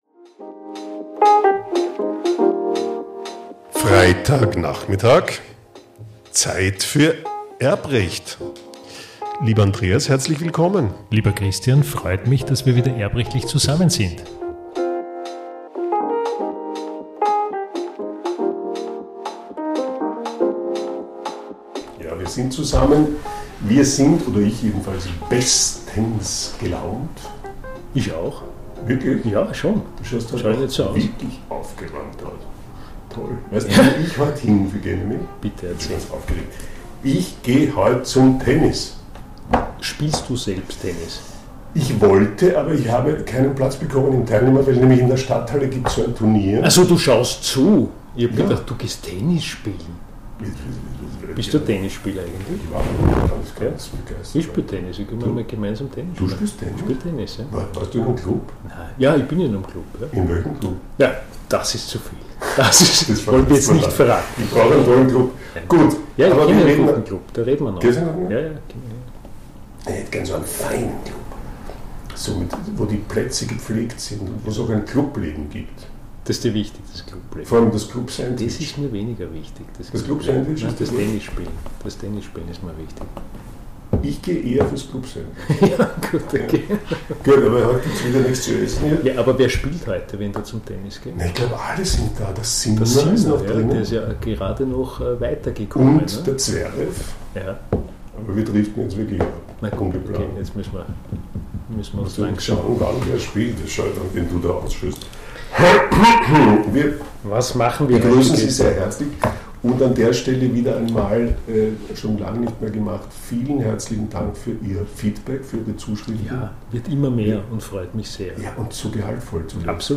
In dieser leicht beschädigten Folge geht es um die Schwierigkeiten, einem Minderjährigen/einer Minderjährigen ein Zinshaus zu schenken oder zu vermachen.